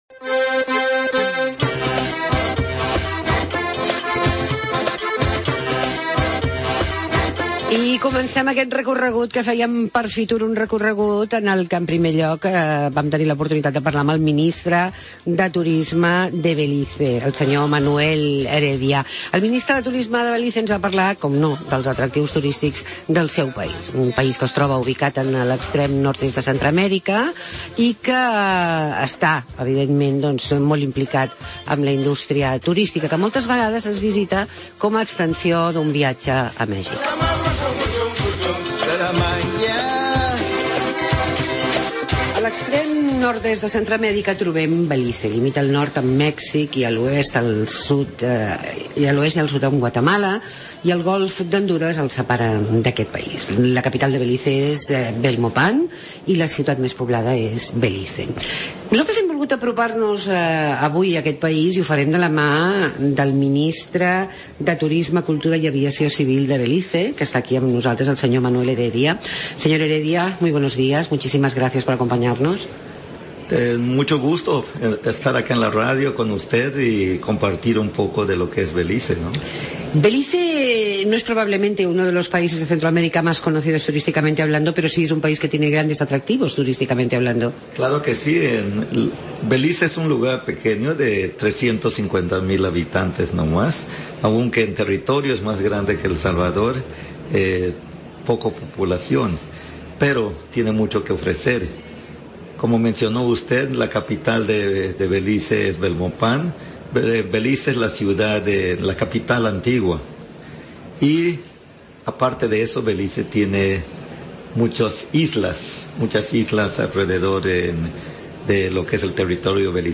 Manuel Heredia, ministro de Turismo de Belice als estudis de Tira Milles a FITUR